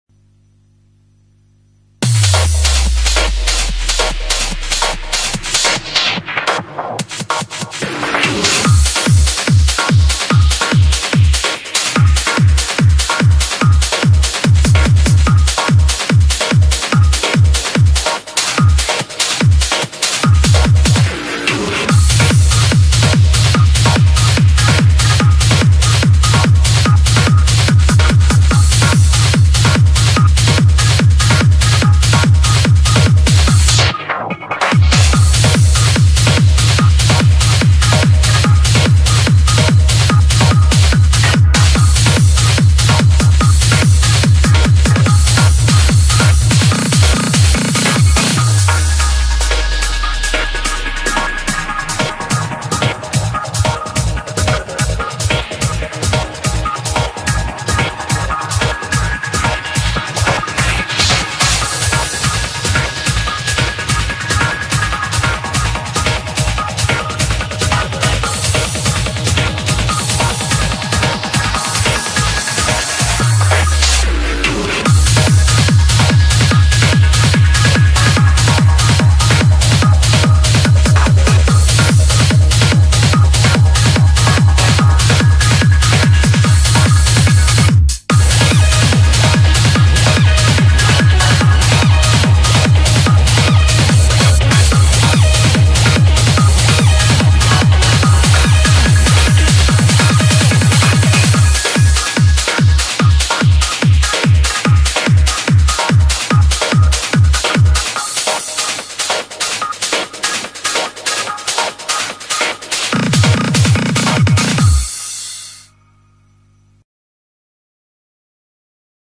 BPM145
Audio QualityPerfect (High Quality)